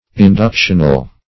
Search Result for " inductional" : The Collaborative International Dictionary of English v.0.48: Inductional \In*duc"tion*al\, a. Pertaining to, or proceeding by, induction; inductive.